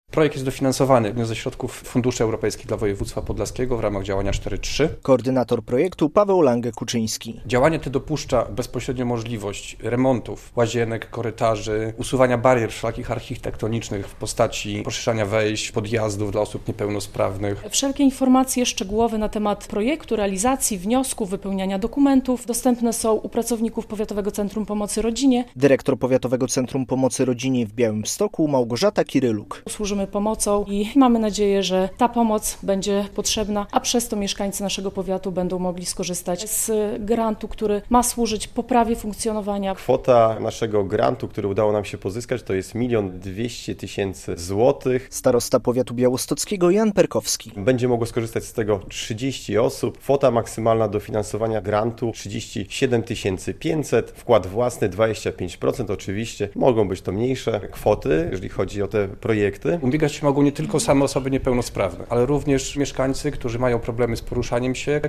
Dotacje na likwidację barier architektonicznych - relacja